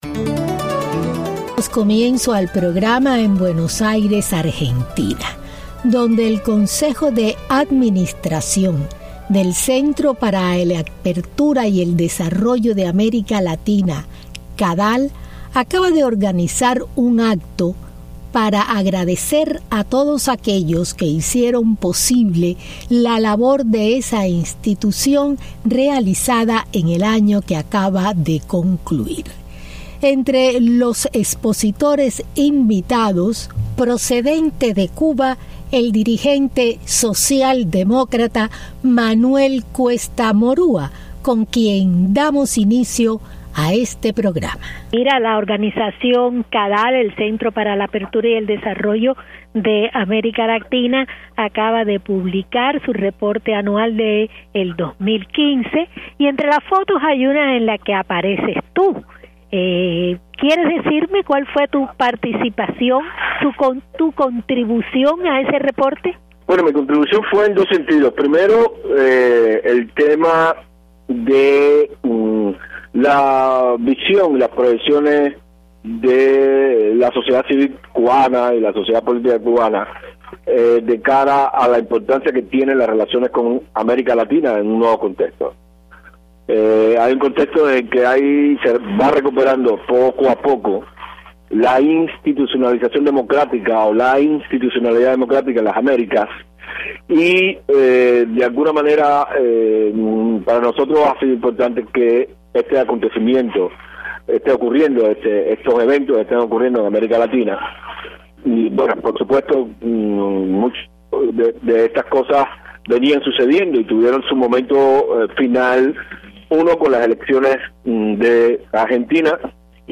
CADAL agradece a colaboradores de sui gestion. Entrevistas